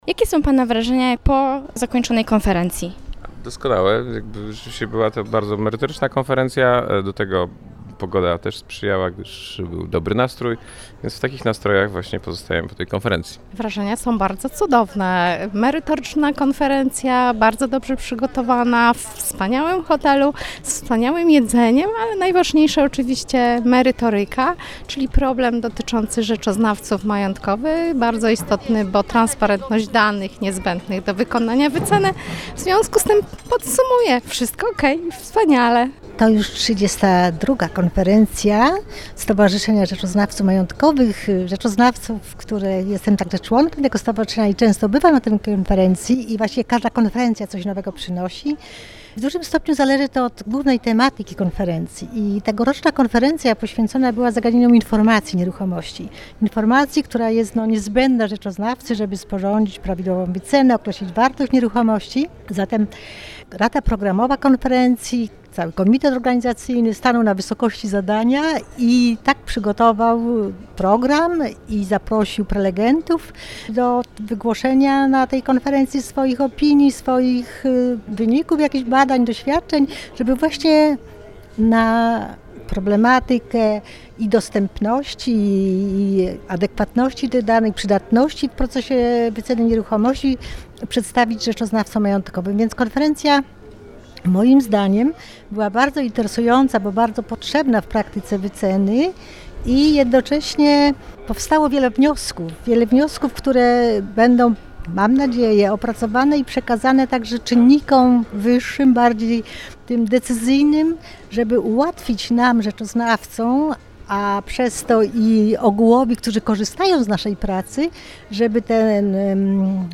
Nasza reporterka rozmawiała także z kilkoma innymi uczestnikami tego wydarzenia.